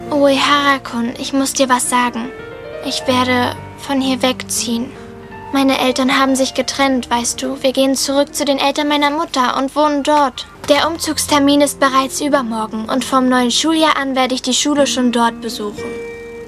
coo-girl.mp3